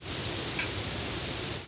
Indigo Bunting diurnal flight calls
Two birds in flight with Boat-tailed Grackle calling in the background.